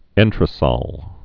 (ĕntər-sŏl, ĕntrə-, ŏn-trə-sôl)